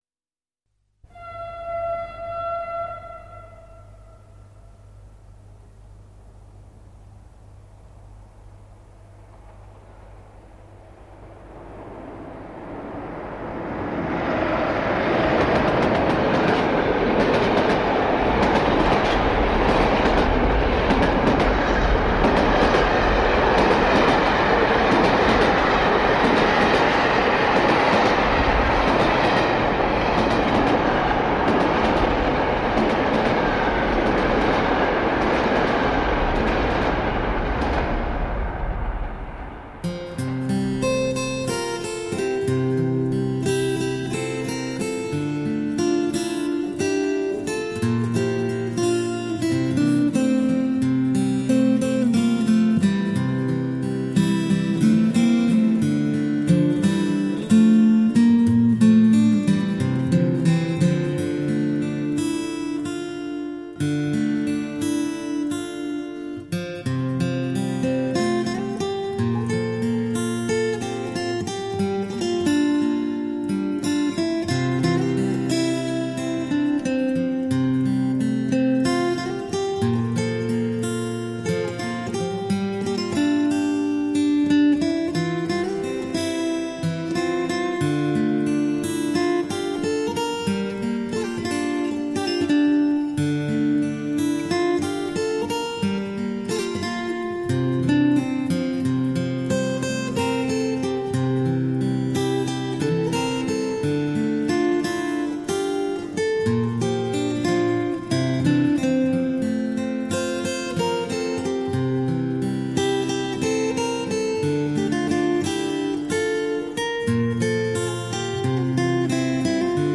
减压静心音乐
清丽如水 飘然若云
琴韵 鸟啼 水流 云动